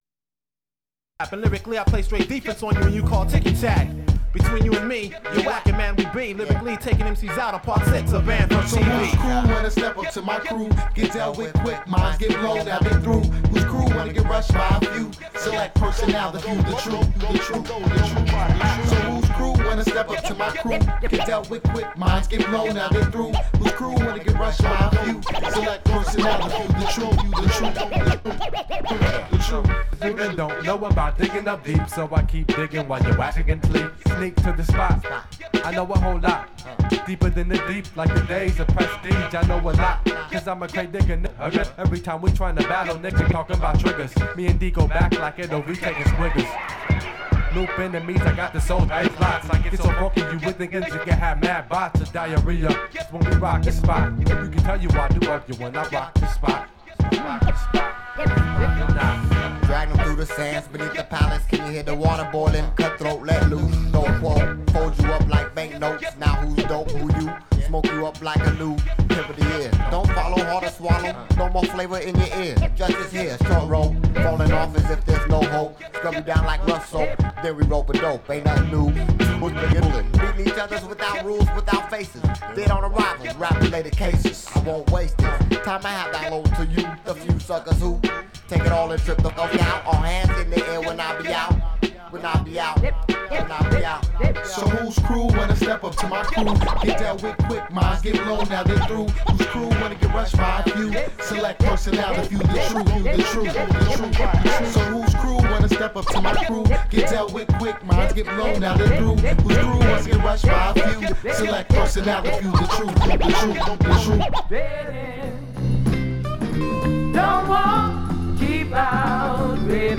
Funk/Soul Hip Hop Reggae/Dub